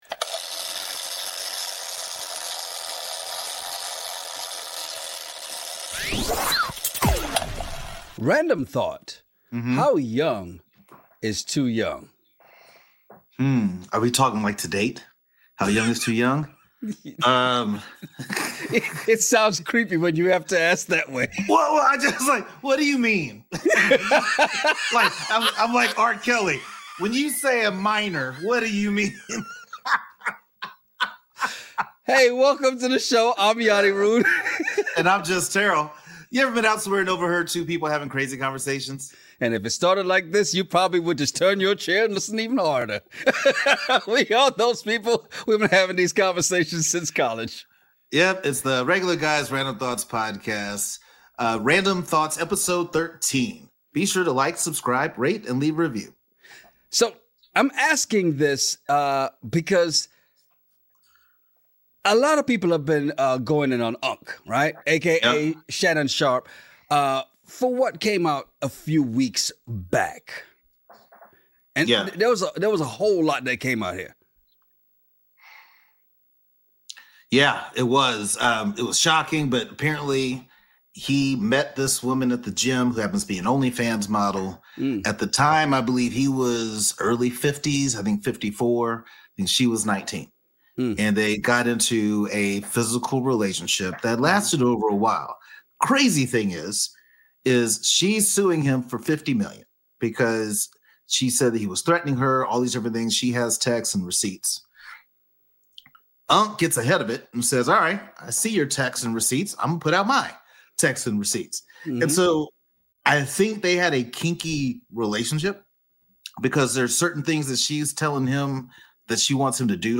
Ever been somewhere and overheard two guys having a crazy conversation over random topics?